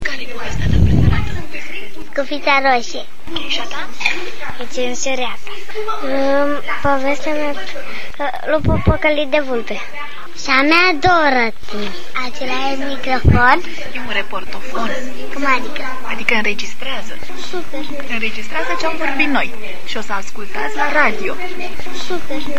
Sarbatoarea cartii pentru copii a umplut la refuz spatiul Galeriilor de Arta Arcadia unde prichindeii din Slobozia au ascultat povestioare spuse de autorii de basme.